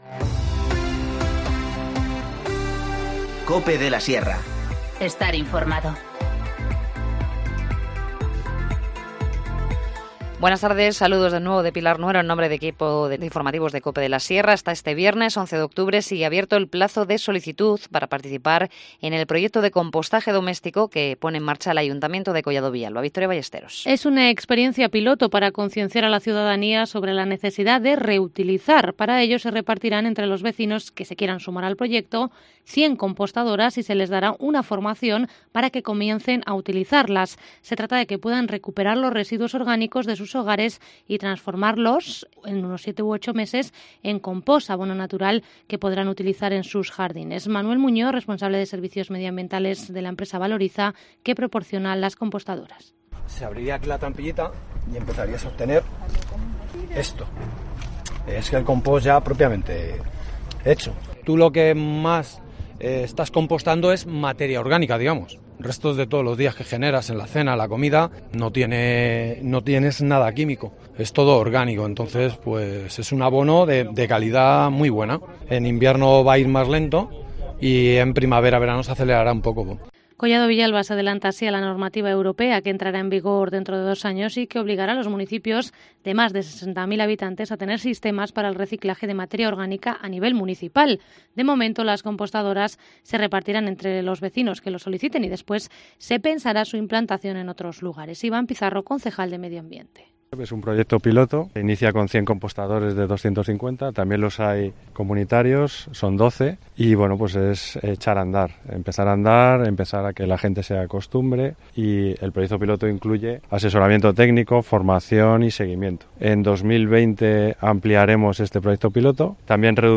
Informativo Mediodía 8 octubre 14:50h